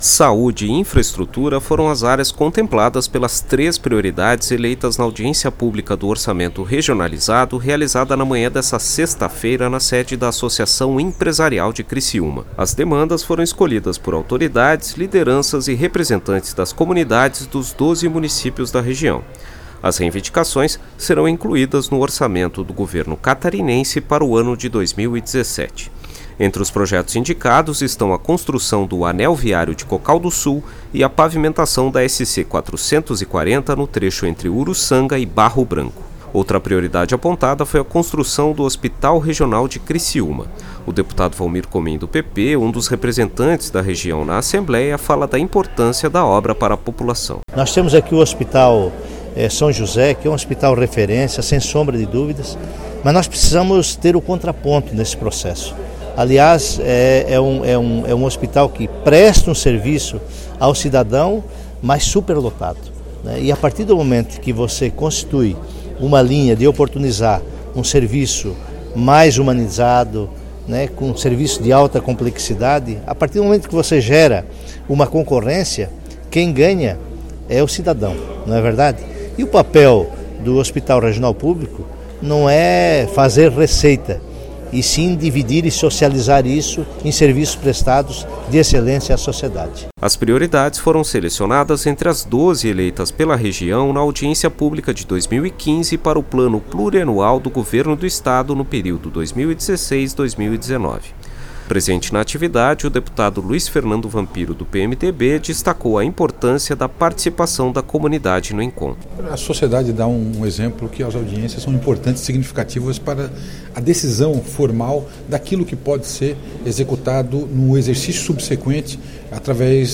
Entrevistas com:
- deputado Valmir Comin (PP);
- deputado Luiz Fernando Vampiro (PMDB).